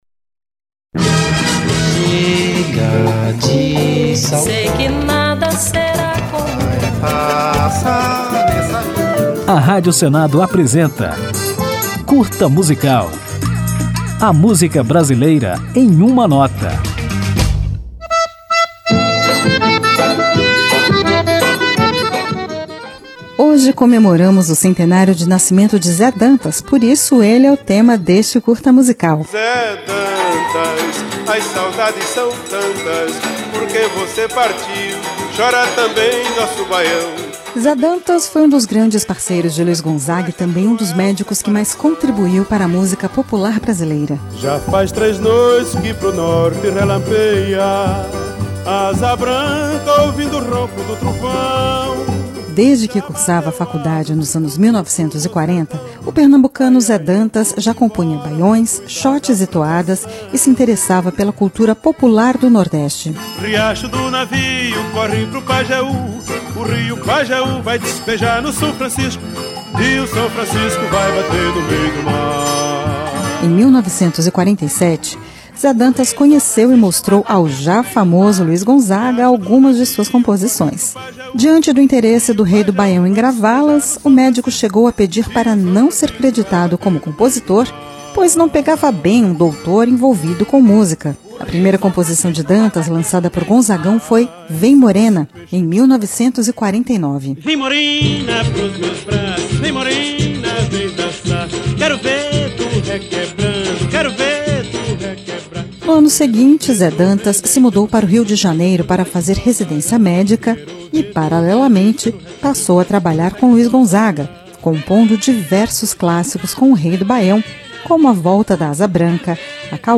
Juntos eles compuseram clássicos do forró
na gravação original de 1950